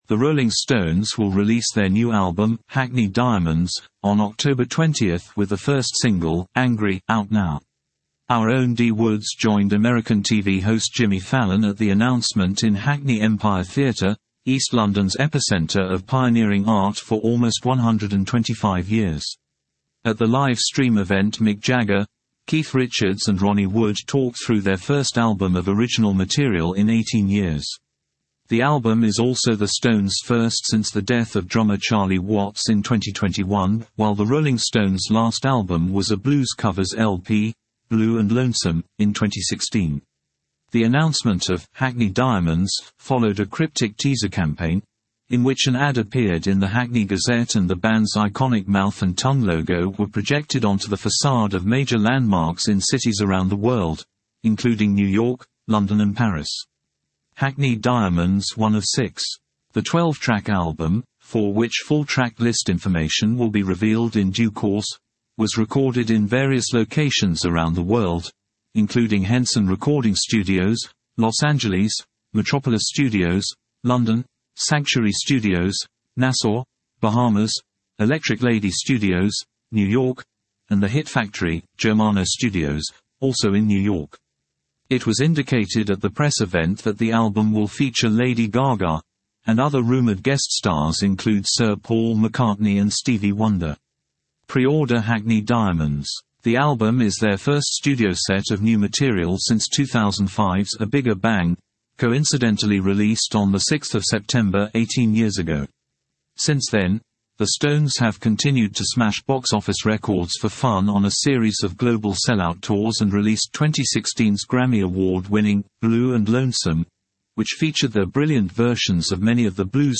At the live stream event Mick Jagger, Keith Richards and Ronnie Wood talked through their first album of original material in 18 years.